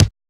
RX KICK.wav